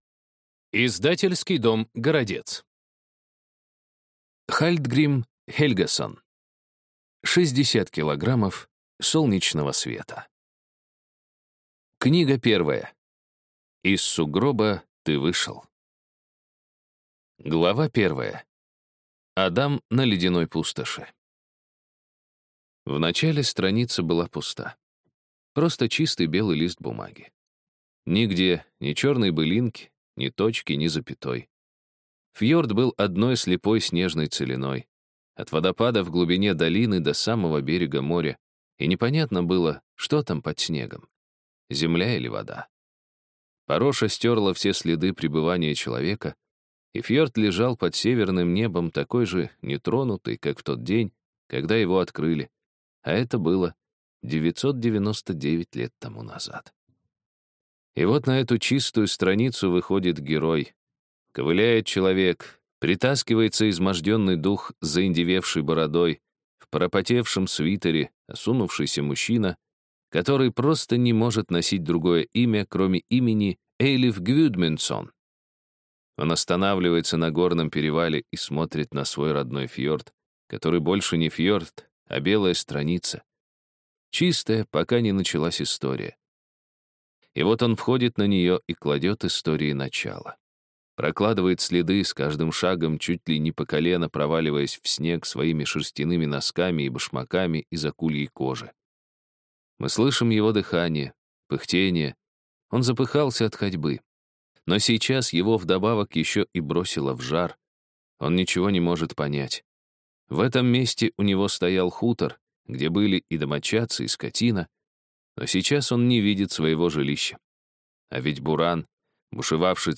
Аудиокнига Шестьдесят килограммов солнечного света | Библиотека аудиокниг